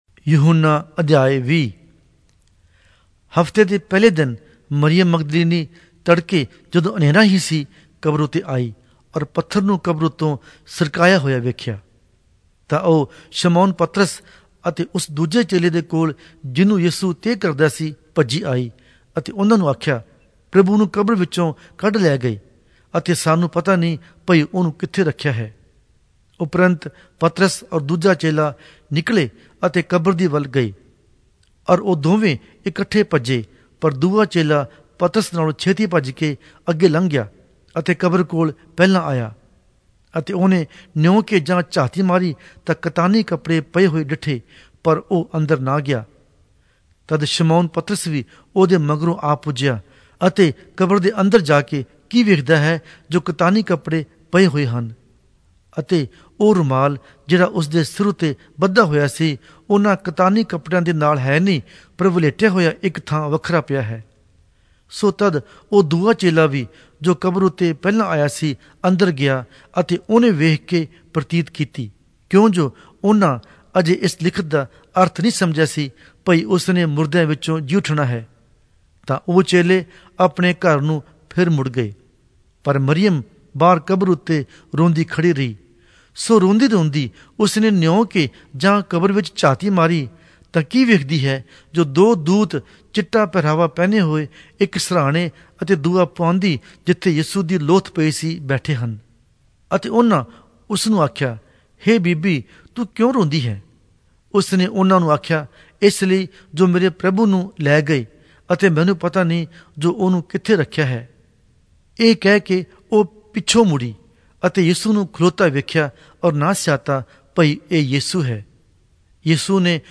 Punjabi Audio Bible - John 3 in Wlc bible version